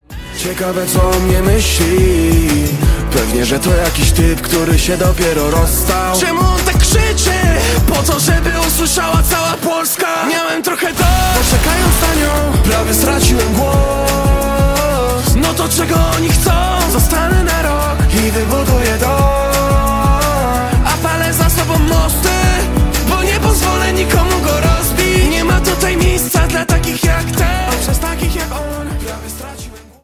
ringtone
Hip-Hop/Rap